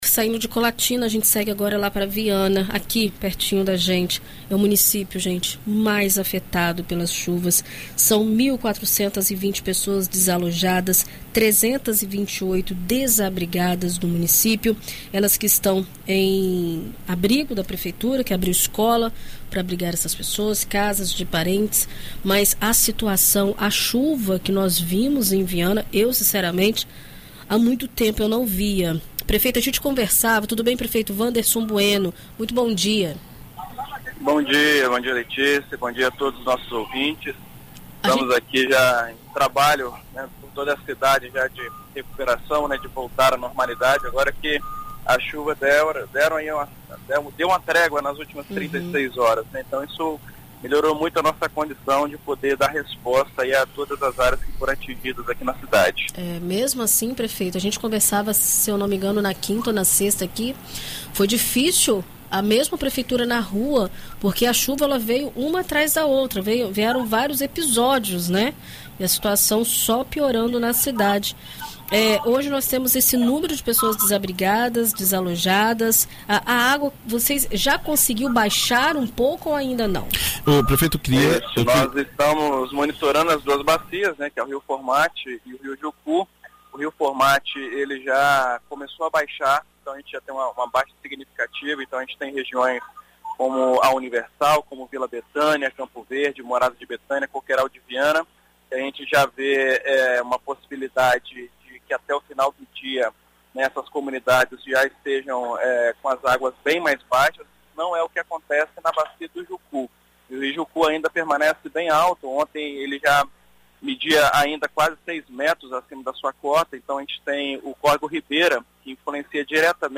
Em entrevista à rádio BandNews FM Espírito Santo, o prefeito de Viana, Wanderson Bueno, explica qual a situação da cidade com as fortes chuvas e o planejamento para os próximos dias.